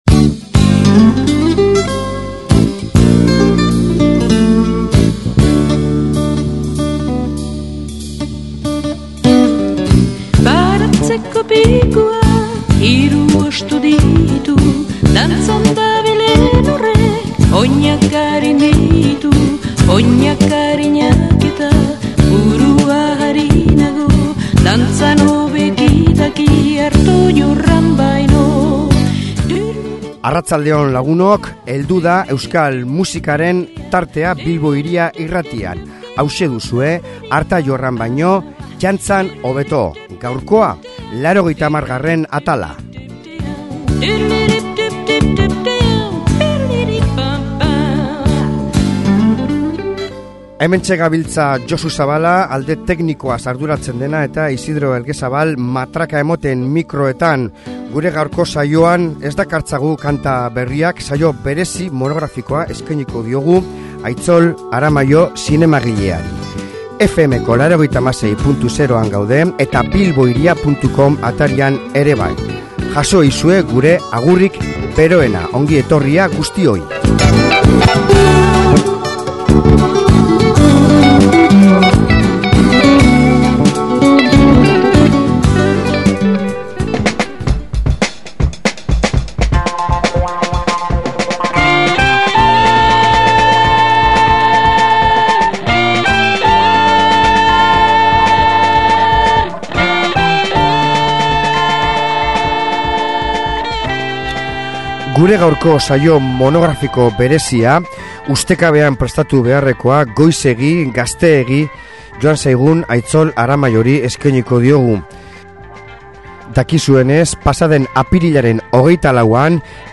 SOLASALDIA
Ondarroan apirilaren 30ean egin zioten omenaldian izan ginen, eta horren kronika dakargu saio monografiko honetan; Bilbo Hiria Irratitik egin nahi diogun omenalditxoa izan dadila.